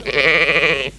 Schafsounds
Dickes Schaf:           böööööööööhhhh           (wav 22 KB)